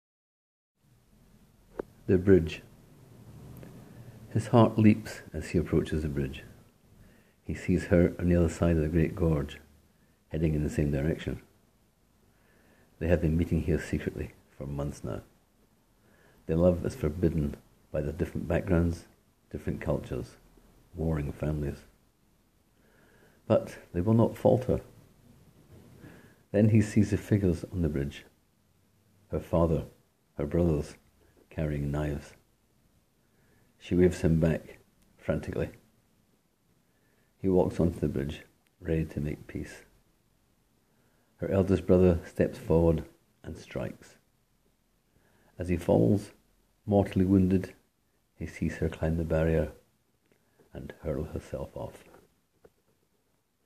Click here to hear me read this 100-word tale: